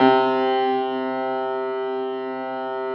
53g-pno06-C1.wav